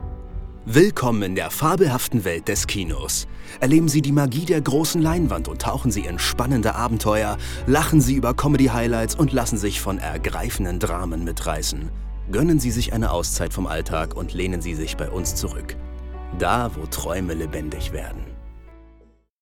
Game